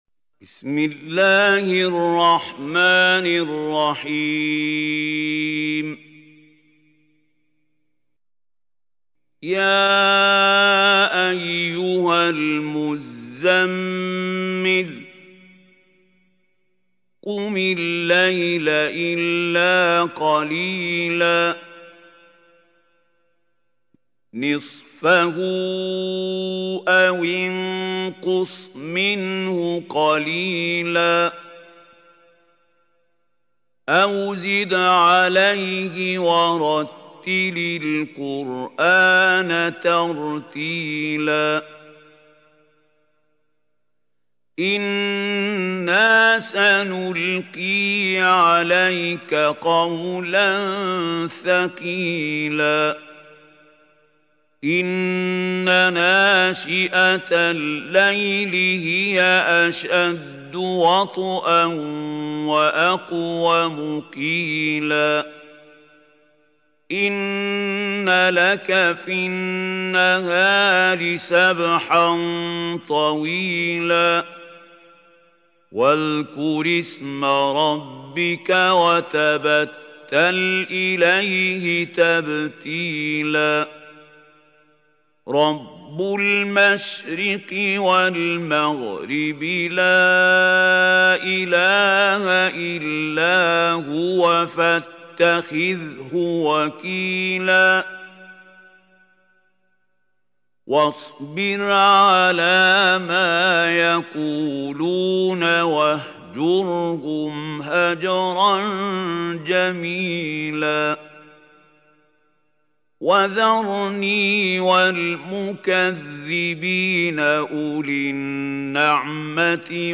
سورة المزمل | القارئ محمود خليل الحصري